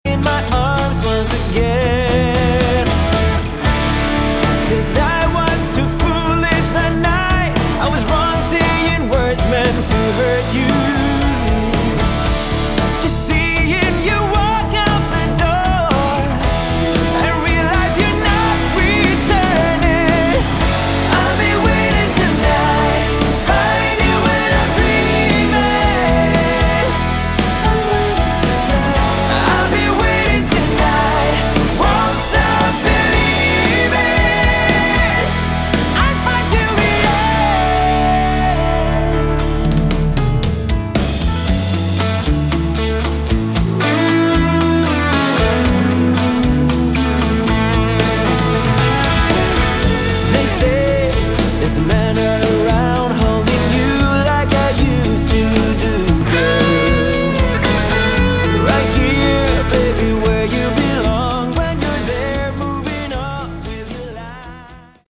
Category: Melodic Rock
lead & backing vocals
guitars
piano & keyboards
bass
drums
Uplifting and extremely melodic as you'd expect.